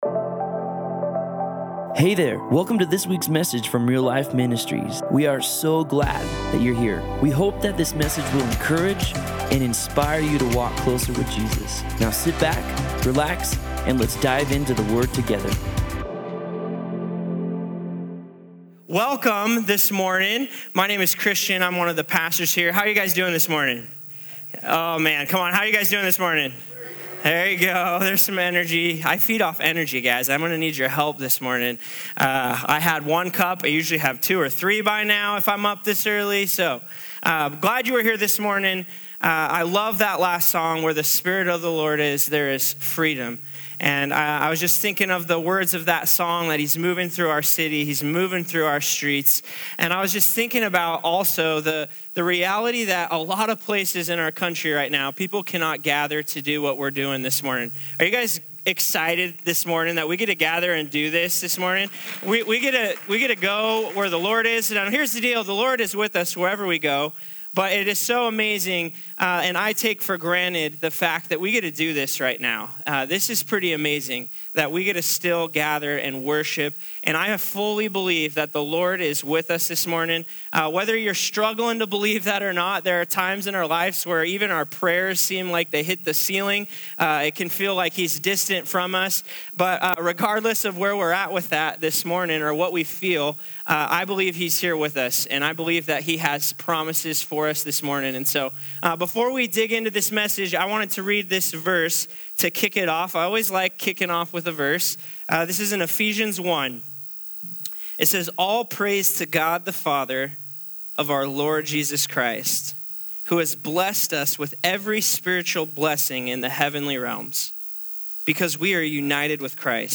Ice Breaker: What is the best/worst white elephant gift you have given/received? ï What was impactful to you from the sermon?